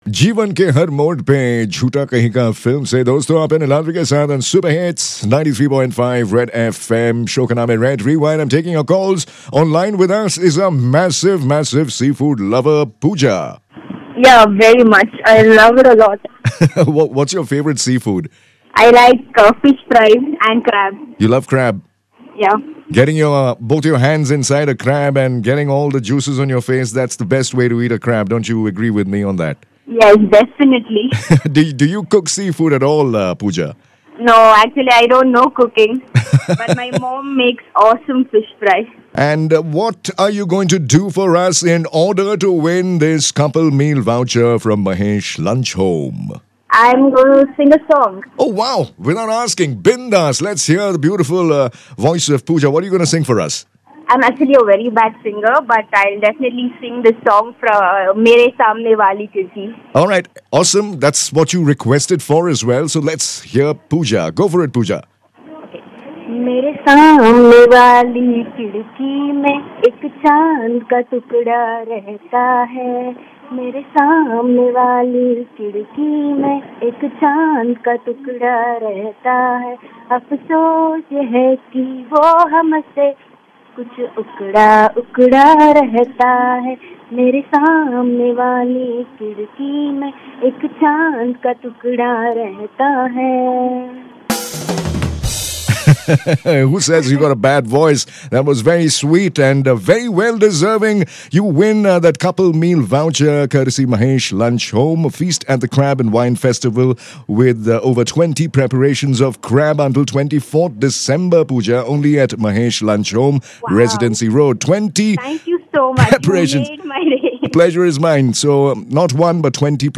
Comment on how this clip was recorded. The midnight singing challenge to win some crab & wine!